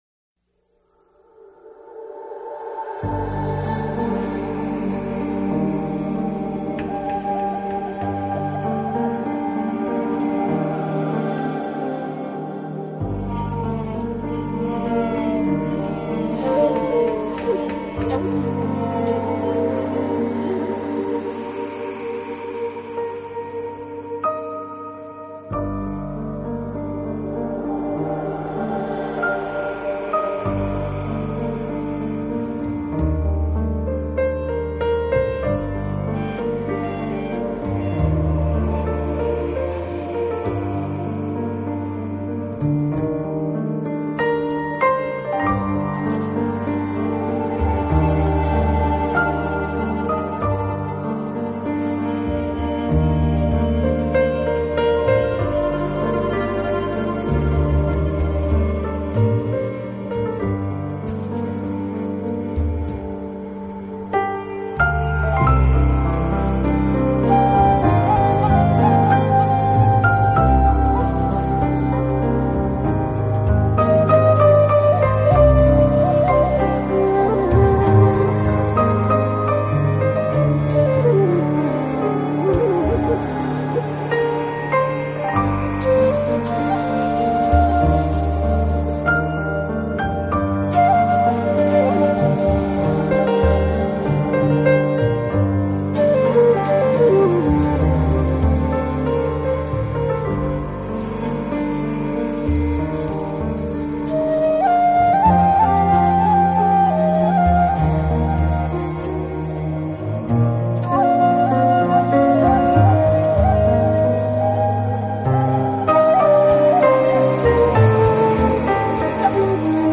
音乐风格：New Age
宇宙里有个生活的殿堂那里蕴藏着生命和与其必须的基本元素，纯净优美的钢
琴配合迷离感伤的长笛，演绎出一片大气唯美的乐章。